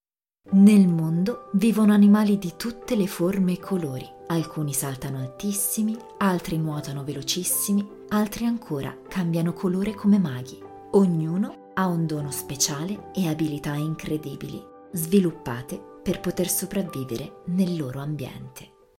Commerciale, Profonde, Polyvalente, Amicale, Chaude
Vidéo explicative